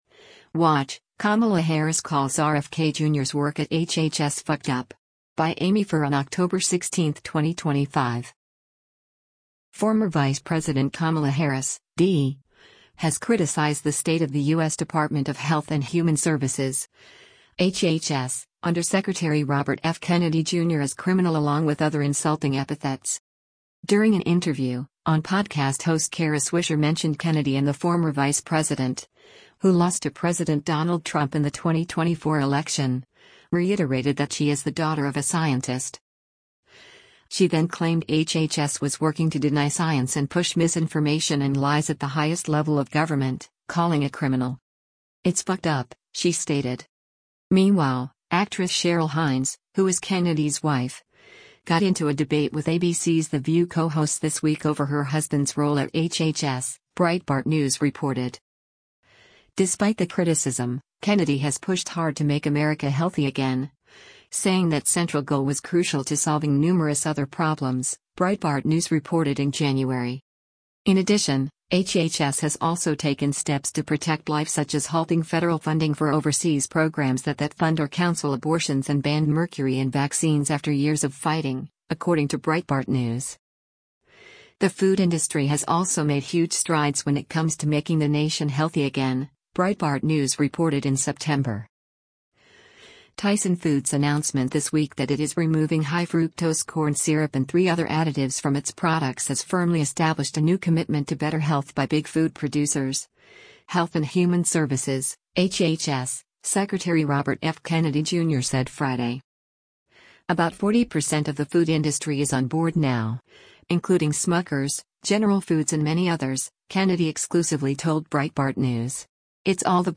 During an interview, On podcast host Kara Swisher mentioned Kennedy and the former vice president, who lost to President Donald Trump in the 2024 election, reiterated that she is the daughter of a scientist.